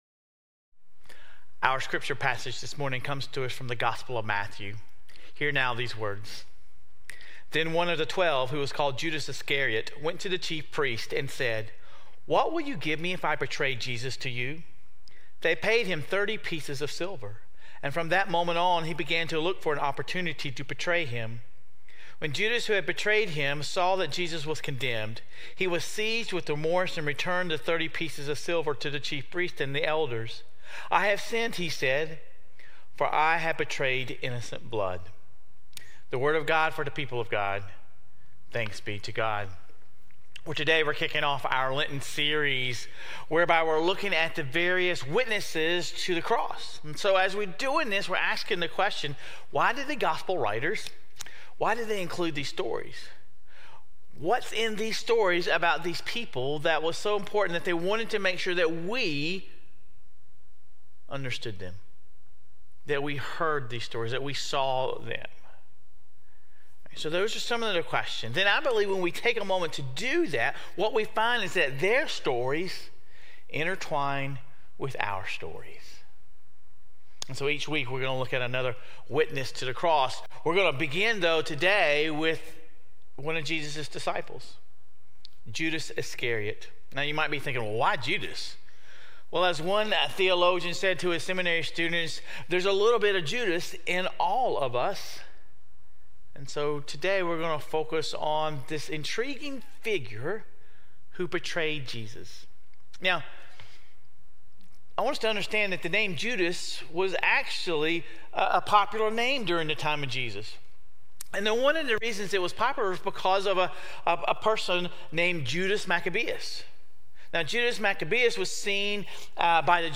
Sermon Reflections: Do you see any part of yourself in Judas?